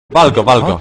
Worms speechbanks
grenade.wav